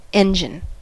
engine: Wikimedia Commons US English Pronunciations
En-us-engine.WAV